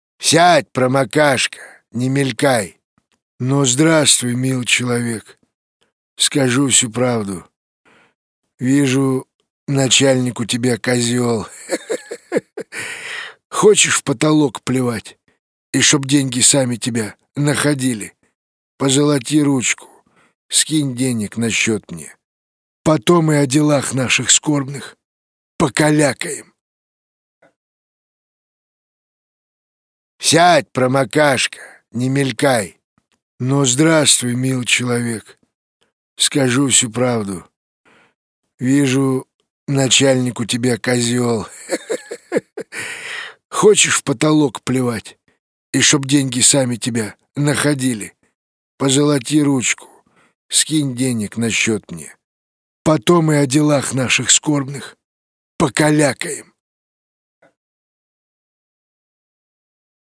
Parodiya_na_Djigarhanyana.mp3